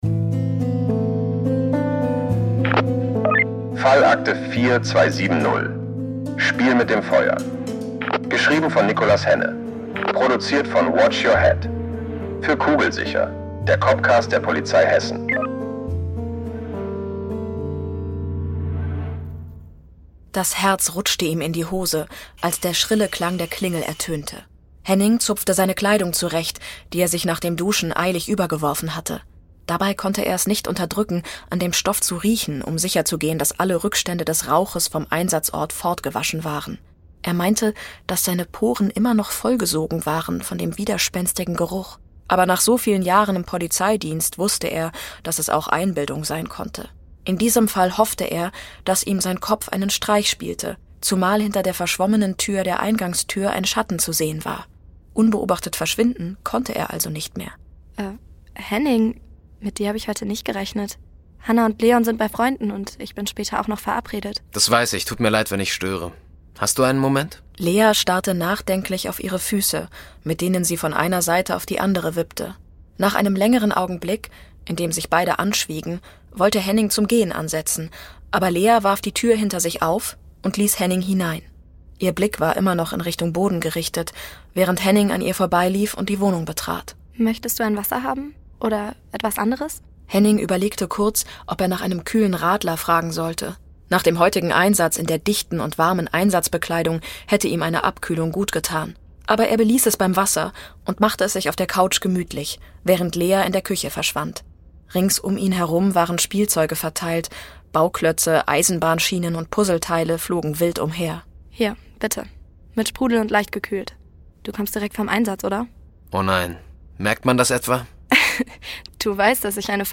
Bei uns dreht sich alles um den echten Polizeialltag, mit echten Cops am Mikro.